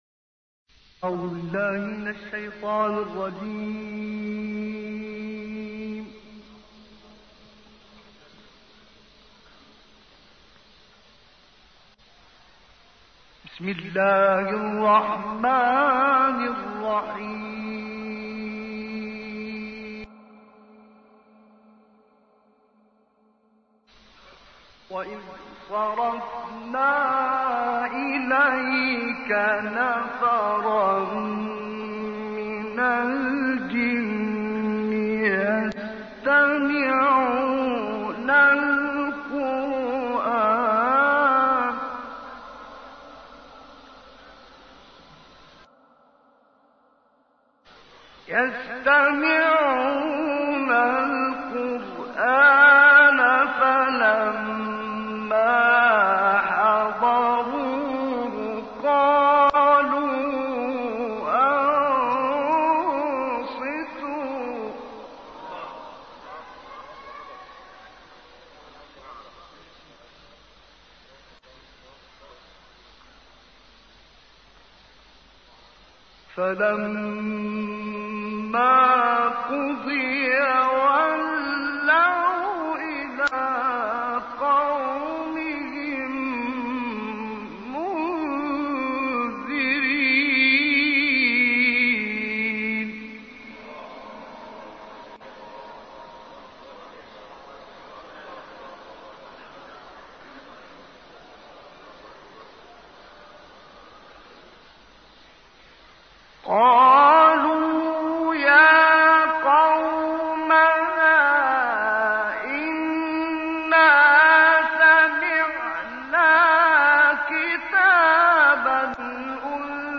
تلاوت احقاف - محمد معروف و شاهکار استاد محمد عبدالعزیز حصان | نغمات قرآن
سوره : احقاف – محمد (ص) آیه : 29-35 * 1-17 استاد : محمد عبدالعزیز حصان مقام : رست * سه گاه * بیات * رست * سه گاه * بیات * نهاوند * رست * سه گاه * رست * نهاوند * چهارگاه * رست * سه گاه * بیات * صبا * بیات […]